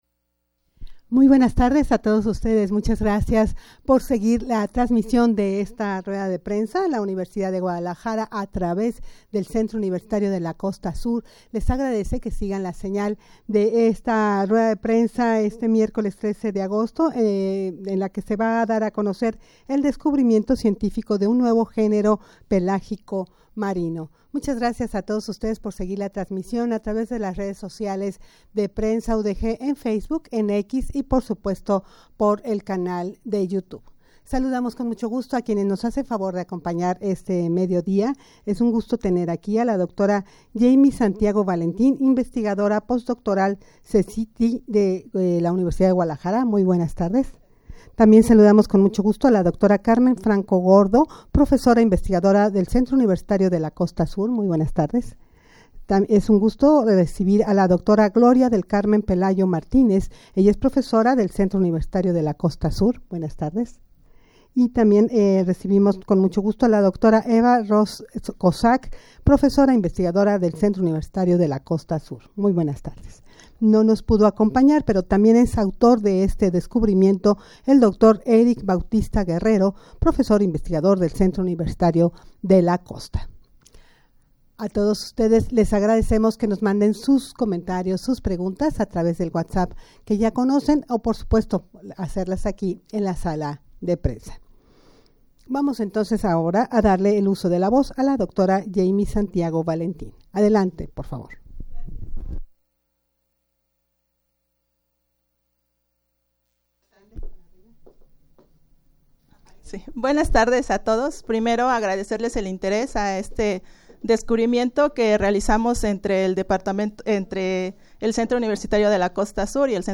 rueda-de-prensa-en-la-que-se-dara-a-conocer-el-descubrimiento-cientifico-de-un-nuevo-genero-pelagico-marino.mp3